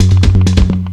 1 Foyer Bass Roll Short.wav